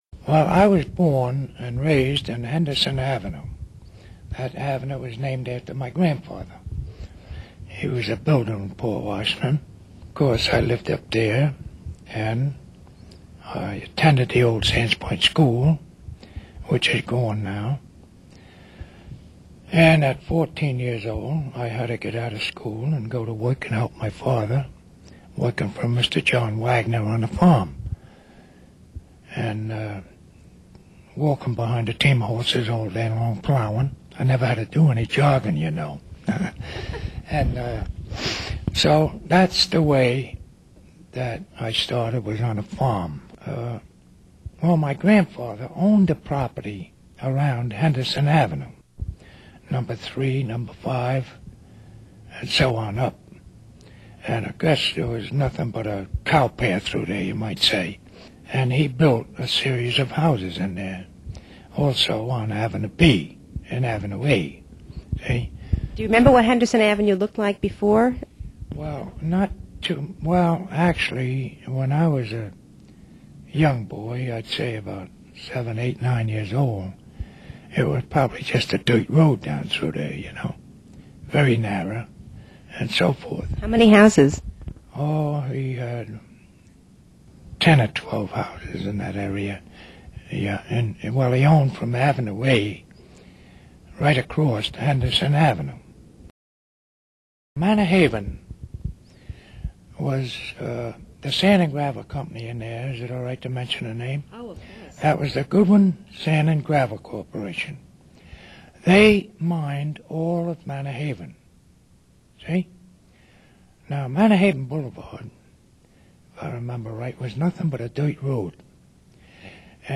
The following interview is one of a series of tape-recorded memoirs in the Port Washington Public Library Community Oral History Program.